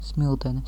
pronunciation
Lv-Smiltene.ogg.mp3